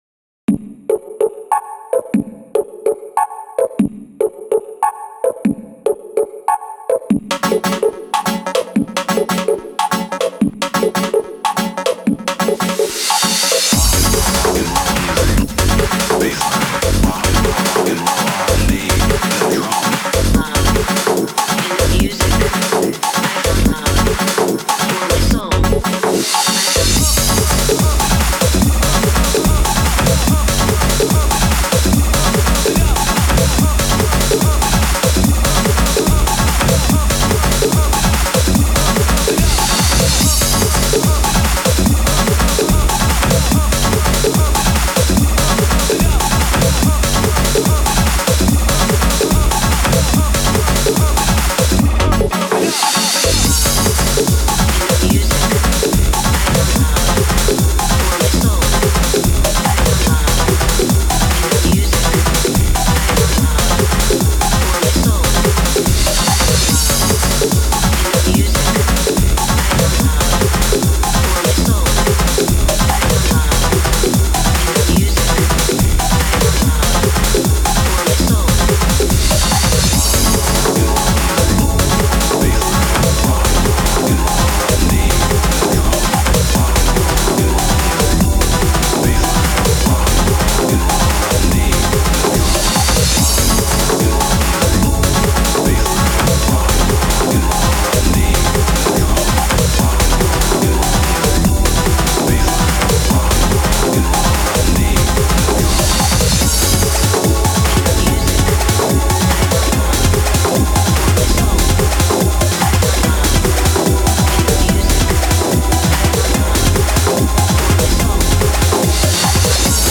Minimalize Tech House
ジャンル的にもご無沙汰だったので第１弾は無機質かつミニマル寄りにしました。